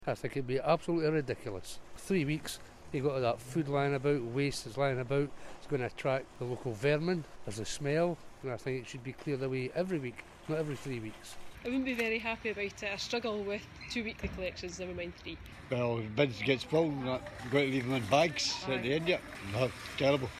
We asked shoppers in Galashiels how they would feel if bin collections are reduced from fortnightly to once every three weeks.